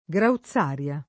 [ g rau ZZ# r L a ]